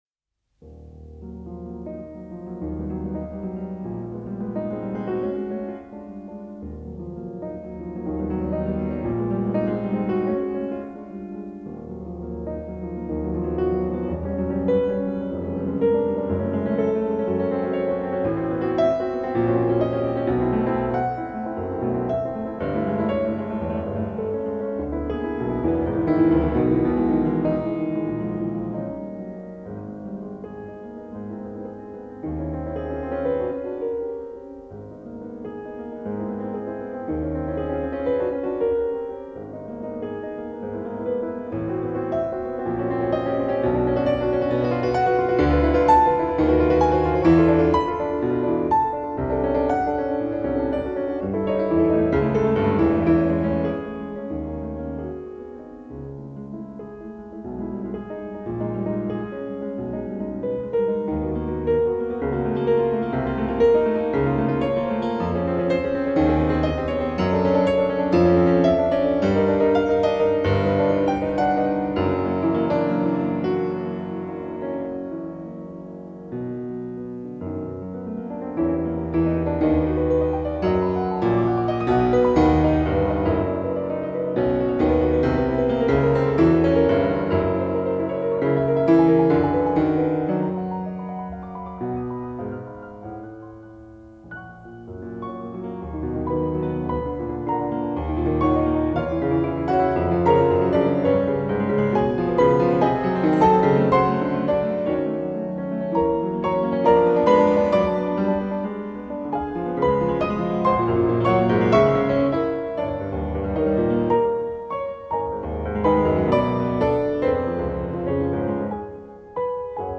RNS2 (MODÉRÉMENT VITE - EXPRESSIF ET MARQUÉ)
RNS2 Sonate Piano P. Dukas (Modérément vite - Expressif et marqué)